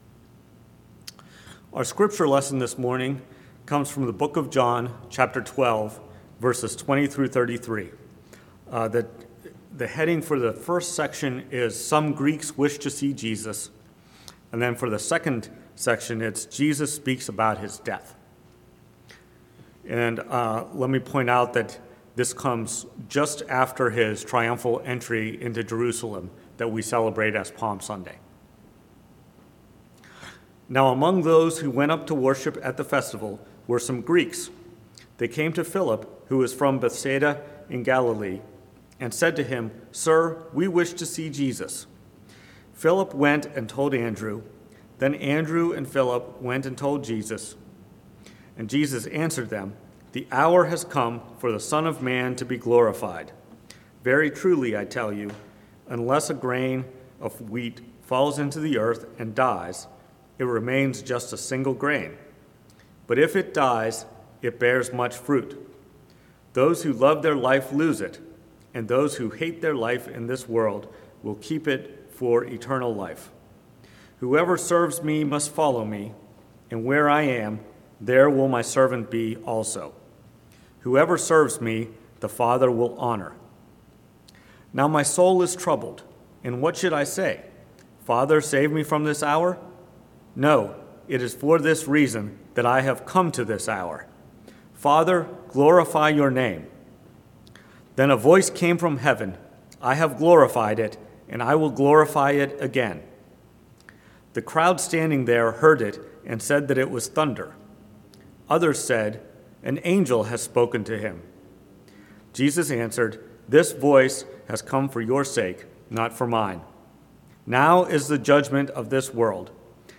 Sermons - Engineering My Faith - Page 10
Preached at First Presbyterian Church of Rolla on the Second Sunday in Lent. Based on Luke 13:31-35.